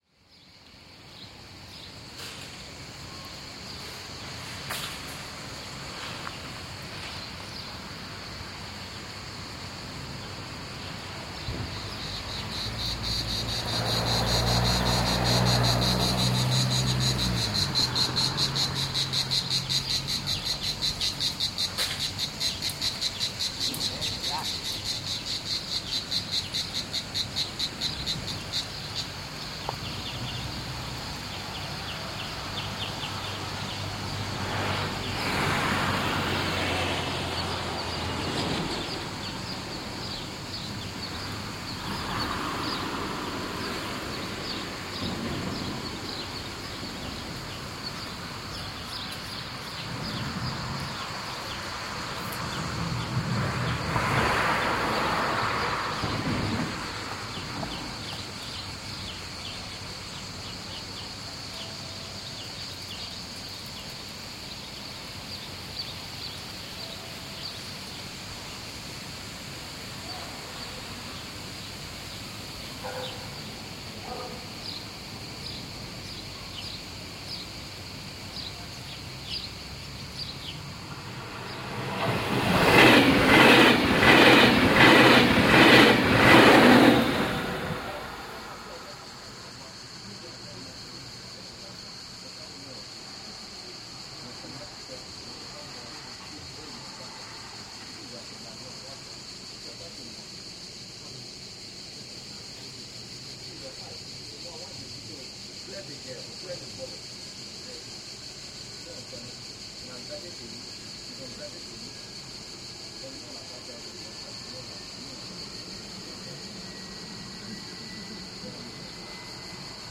Stazione Le Piagge in Florence, Italy.